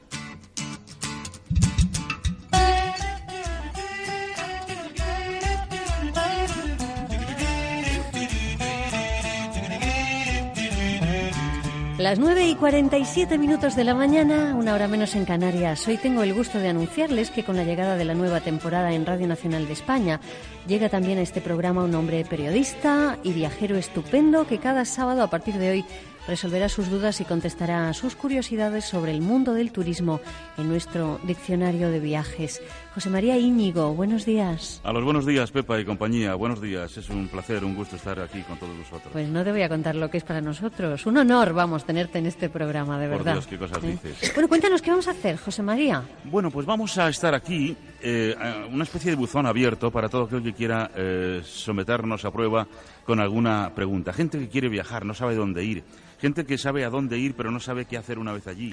Entreteniment
Fragment extret del programa "No es un día cualquiera" de RNE emès el dia 5 de maig del 2018 i publicat al portal RNE Audio